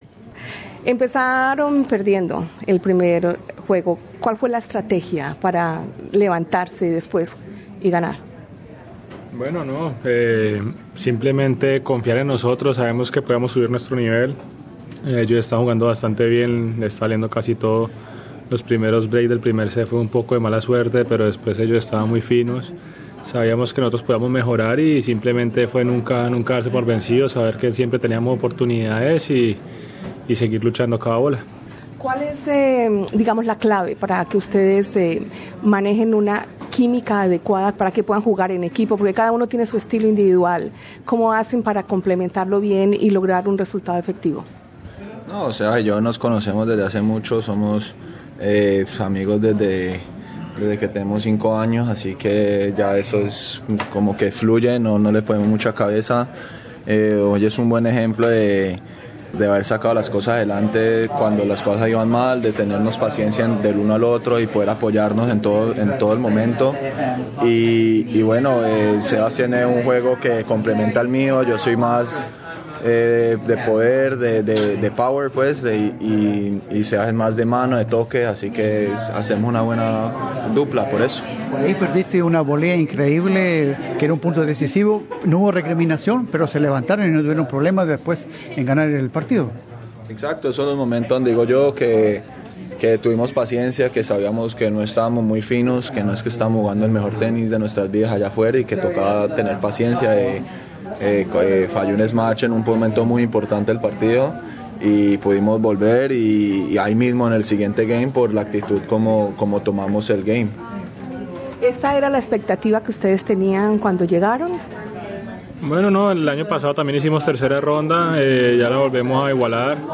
Escucha aquí la entrevista en español con los dos jugadores, al finalizar el partido.